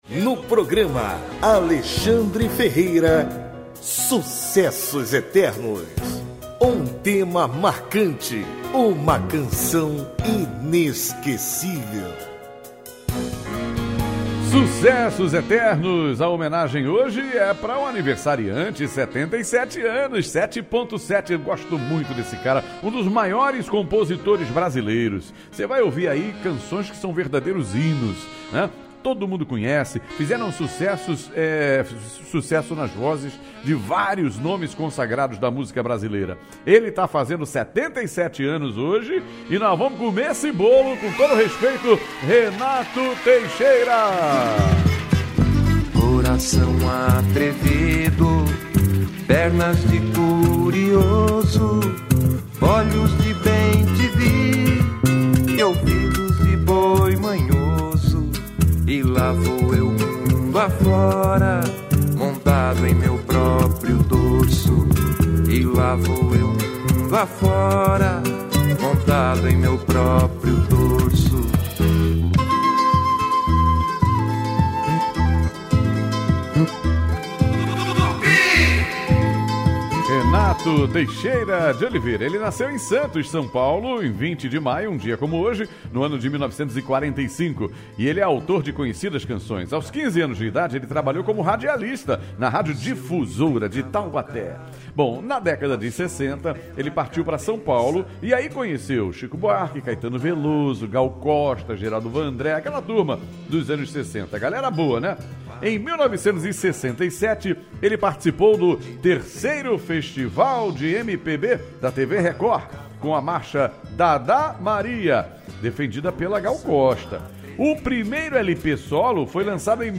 O cantor é dono de sucessos como “Cavalo Bravo”, “Romaria” e “Tocando em Frente”. Ouça a homenagem narrada
A atração vai ao ar de segunda a sábado, a partir de meia-noite, com muita interação, bate-papo, informação e boa música.